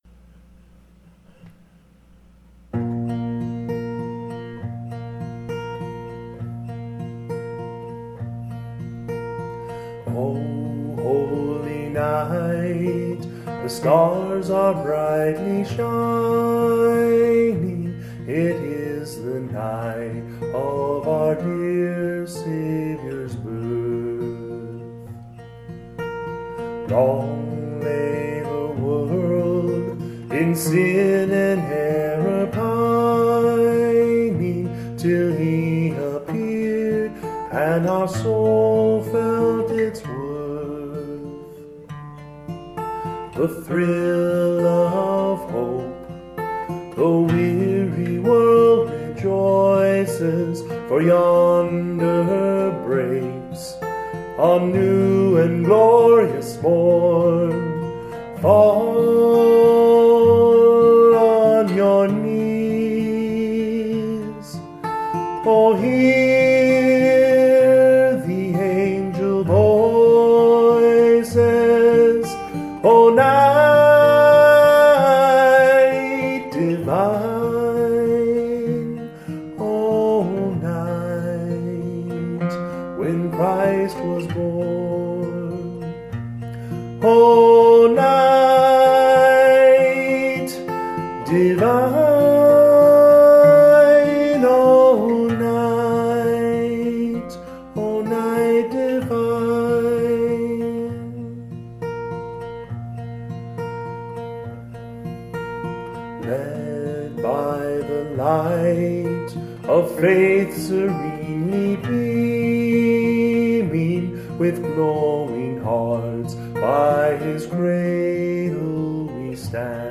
Most of the songs were recorded in one take - so I haven't spent a ton of time fixing mistakes and such (apologies); in fact, part of my goal was to focus on music in-the-moment as opposed to saccharin/glitzy productions.